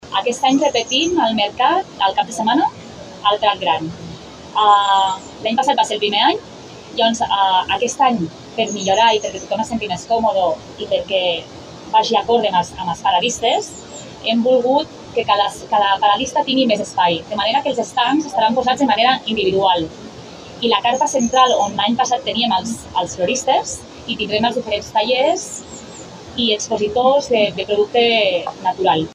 La 32a edició d’Andoflora torna de nou al Prat Gran de la Massana, amb un redisseny de l’espai per donar més amplitud a cadascun dels estands de jardineria i floristeria. Hi haurà més d’una vintena d’operadors i també es muntarà un envelat dedicat als productes naturals relacionats amb el món de la botànica, la gastronomia, la cosmètica natural o la joieria, tal com ha explicat la consellera de Turisme, Mònica Solé.